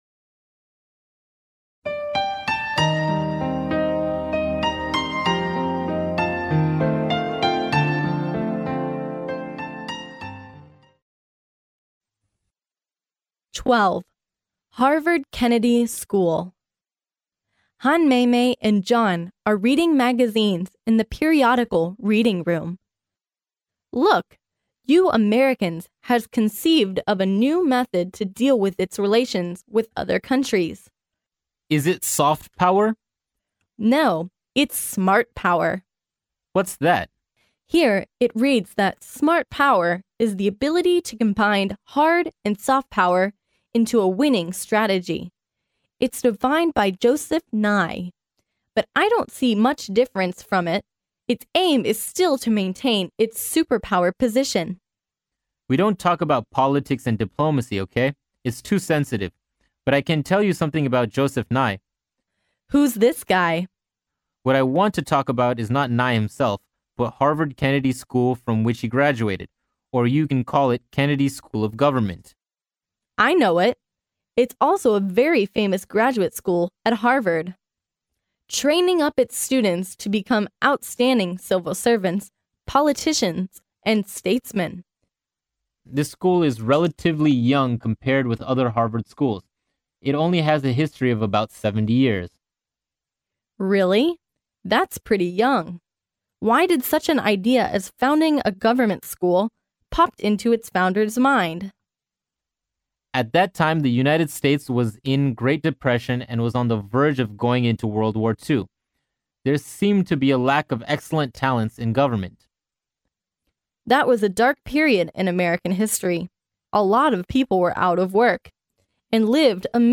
Han Meimei and John are reading magazines in the periodical reading room.